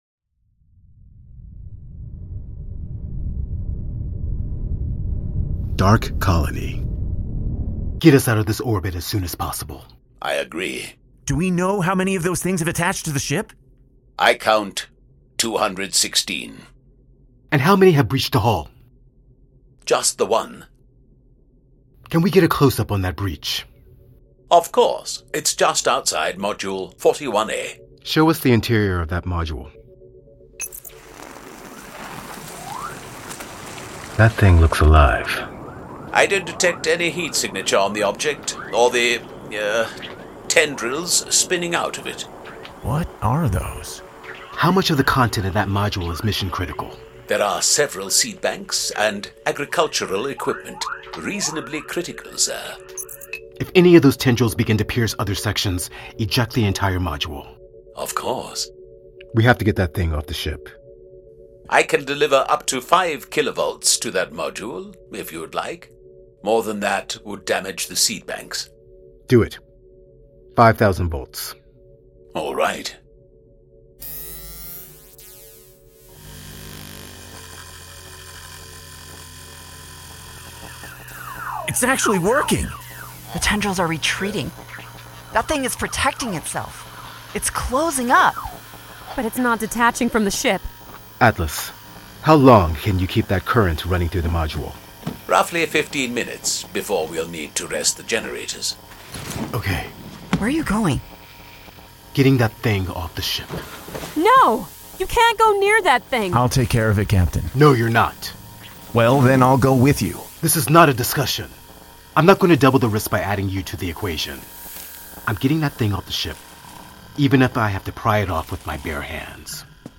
Voice Over
Studio: Sennheiser Microphone